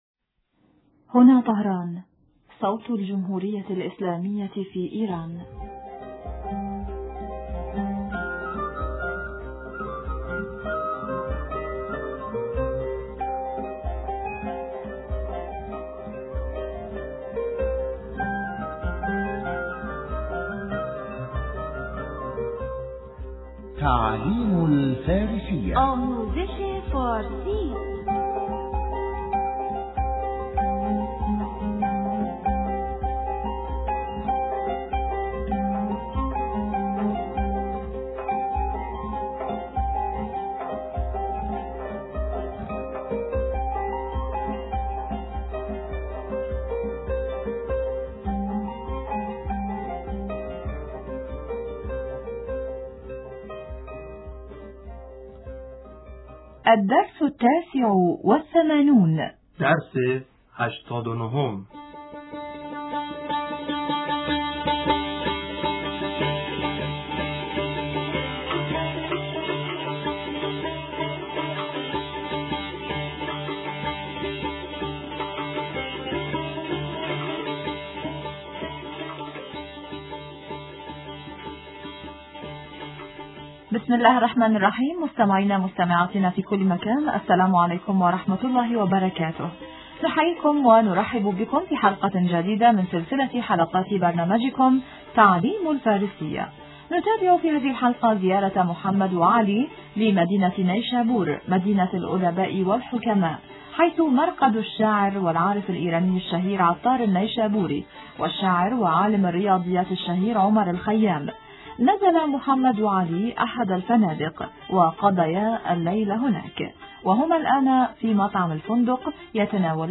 إذاعة طهران- تعلم الفارسية- بحث حول الاجواء و المعالم في مدينة نيشابور